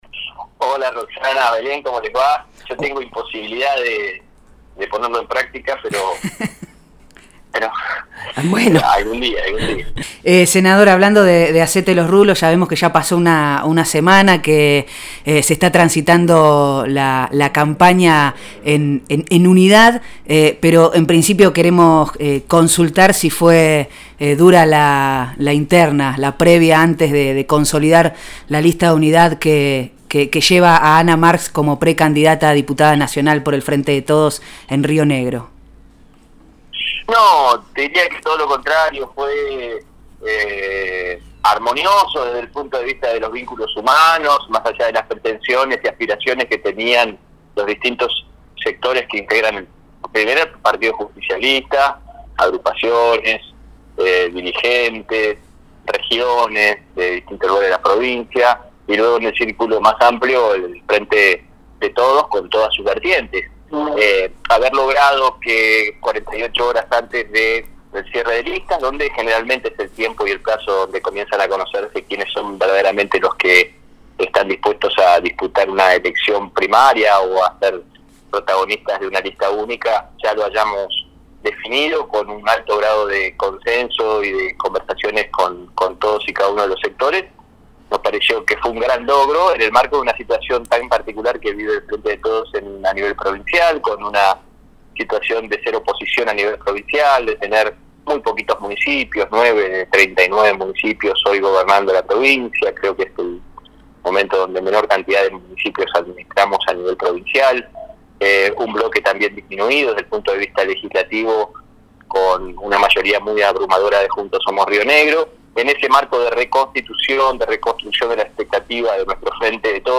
El Senador Nacional por Río Negro del Frente de Todos se sumó al aire de Hacete los Rulos.